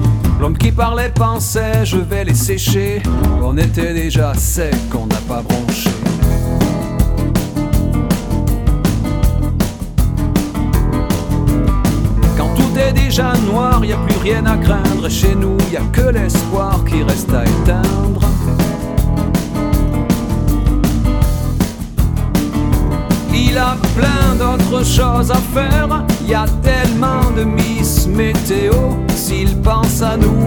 0 => "Chanson francophone"